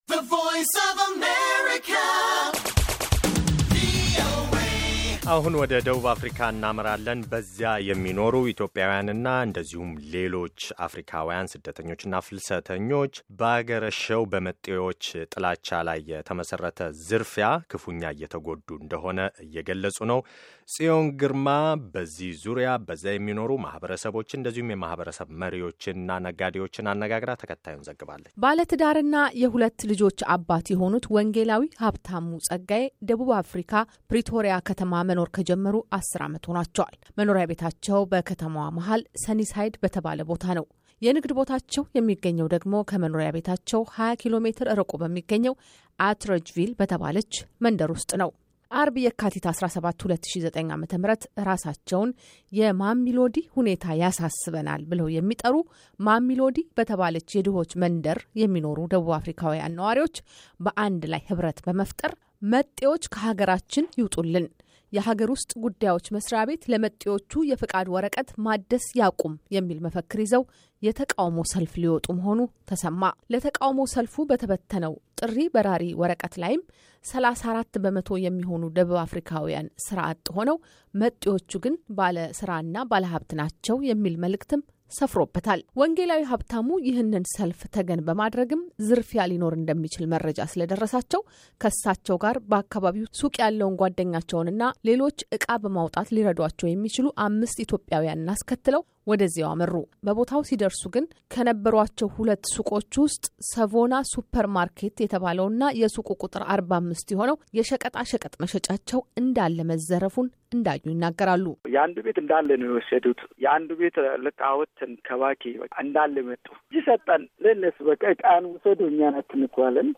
በደቡብ አፍሪካ ንብረታቸውን በዘራፊዎች የተቀሙ ሁለት ኢትዮጵያውያን ይናገራሉ